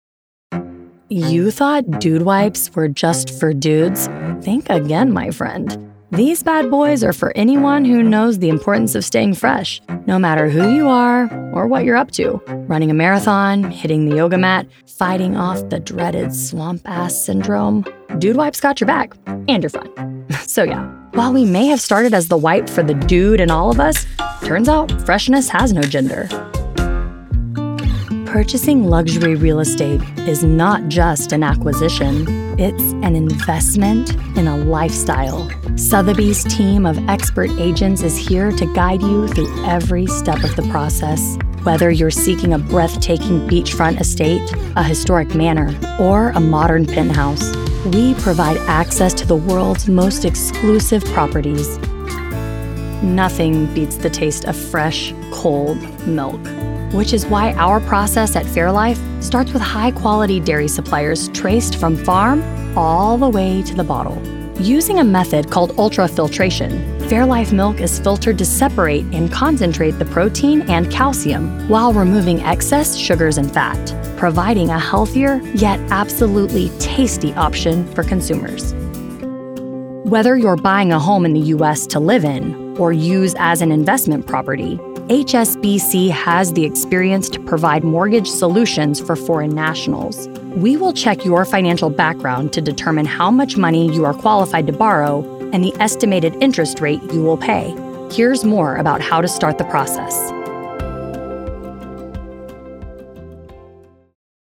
Raspy, real person, millennial with engaging, fun personality.
Corporate Narration
Neutral, Neutral English, General American English, Southern
My sound is warm, raspy, approachable, conversational, confident, and sincere—capable of humor, nuance, and emotional depth.